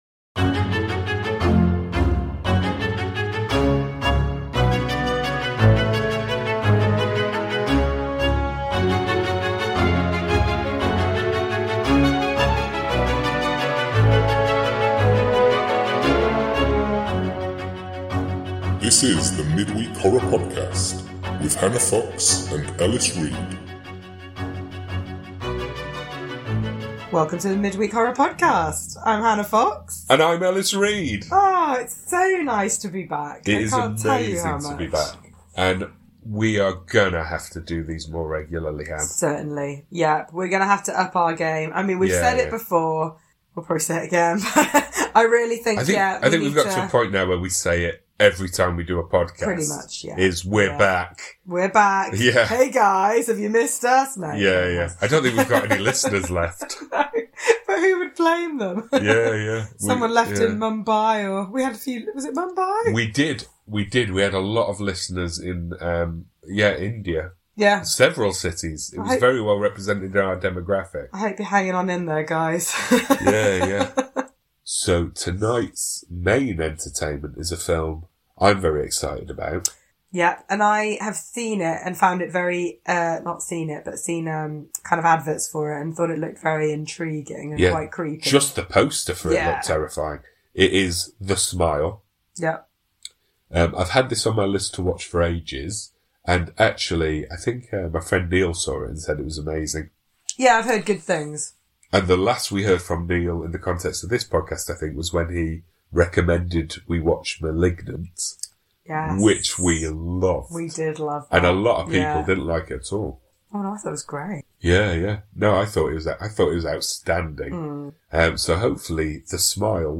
This week we talk about SMILE (with full spoilers) and the 2022 Hellraiser Reboot (with possible mild spoilers). Apologies for the sound quality issues - the middle section is definitely a bit rough!